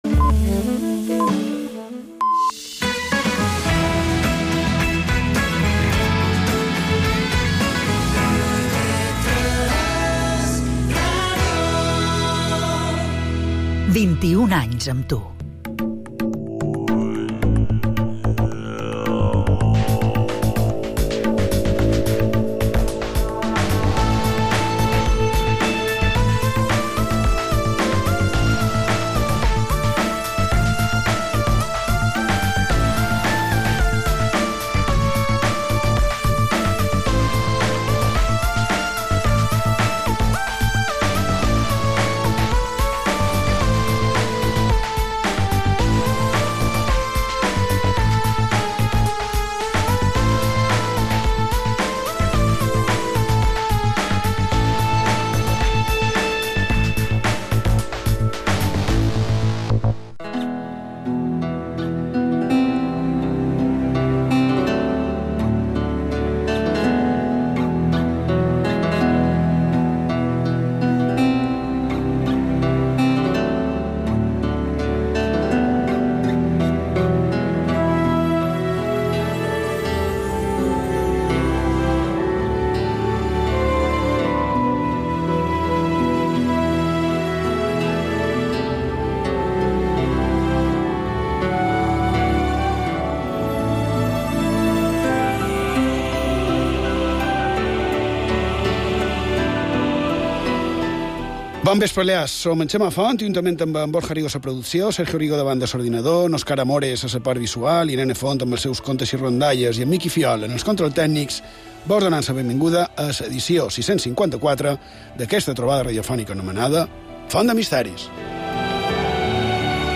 El programa s’enfronta a la quinzena temporada amb un enfocament seriós, rigorós, referències històriques i veus d’experts.